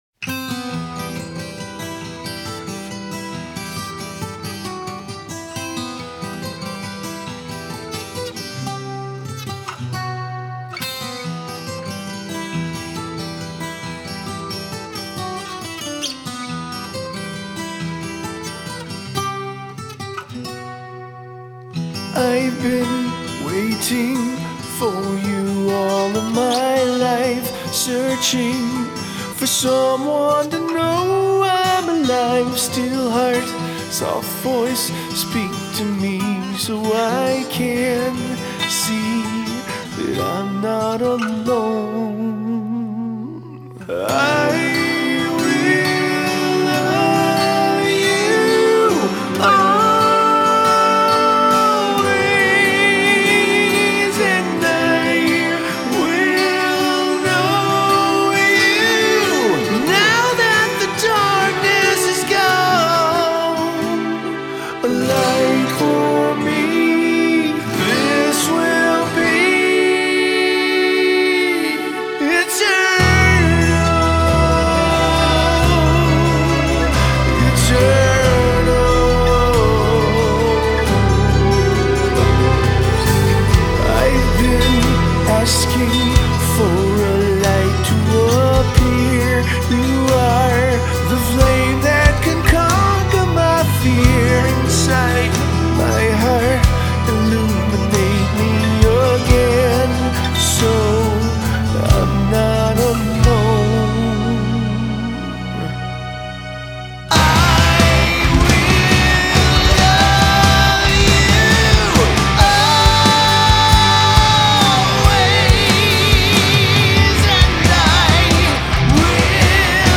Guitar solo of the song "Eternal"